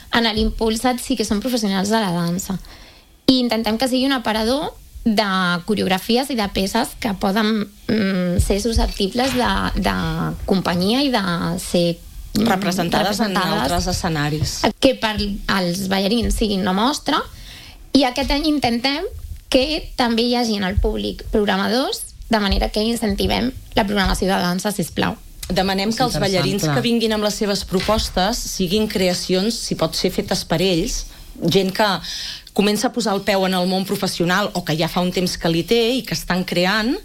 han passat pels micròfons del matinal de RCT